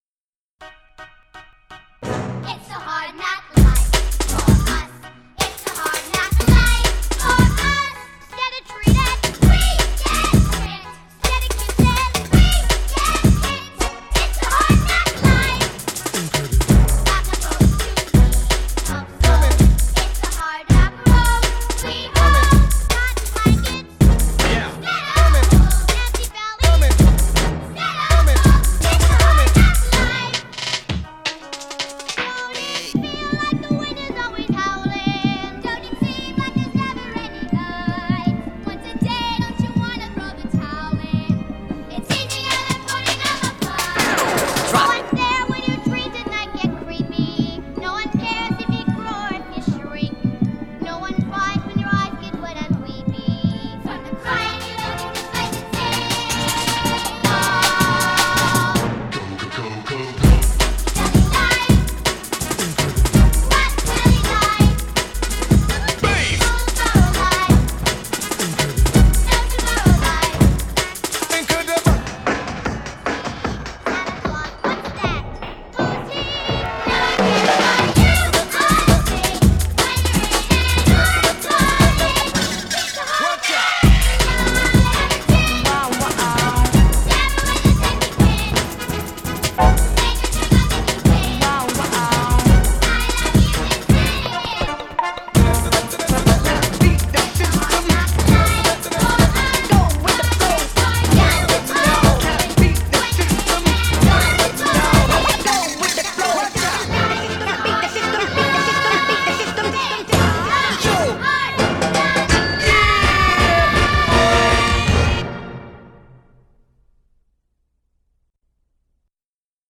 mashed-up with drum & bass embellishments.